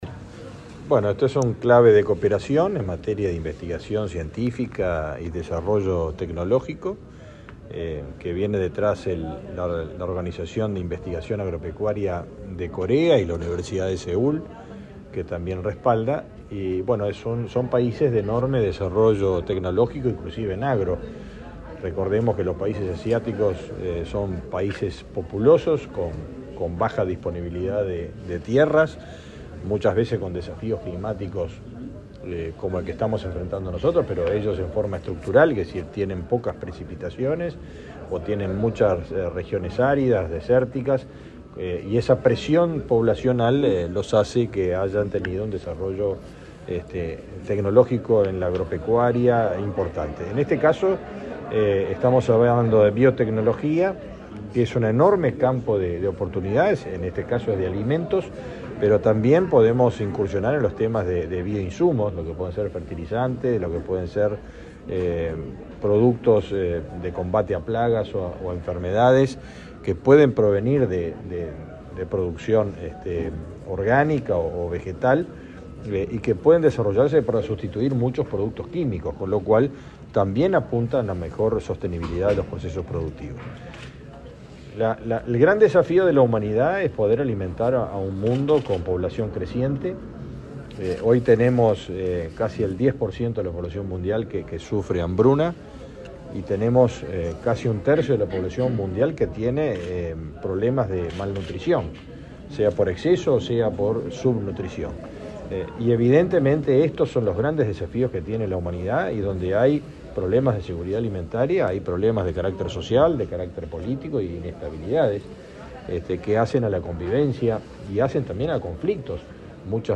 Declaraciones del ministro de Ganadería, Fernando Mattos
Declaraciones del ministro de Ganadería, Fernando Mattos 22/06/2023 Compartir Facebook X Copiar enlace WhatsApp LinkedIn Representantes del Ministerio de Ganadería, Agricultura y Pesca, la Oficina de Planeamiento y Presupuesto y la Administración de Desarrollo Rural de la República de Corea firmaron, este jueves 22, en la Torre Ejecutiva, un memorando de entendimiento para cooperar en biotecnología agrícola. El titular de la citada cartera, Fernando Mattos, explicó a la prensa la importancia del acuerdo.